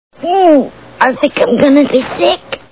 The Simpsons [Lisa] Cartoon TV Show Sound Bites